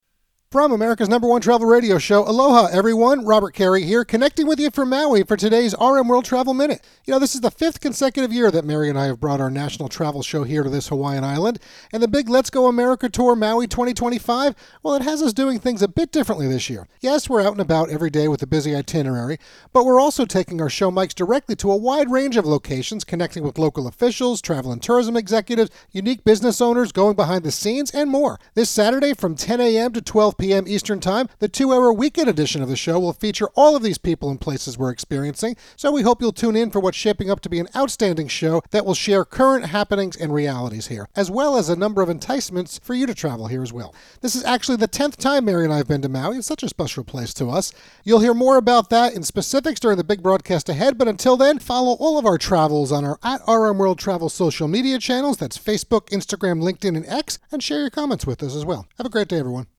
America's #1 Travel Radio Show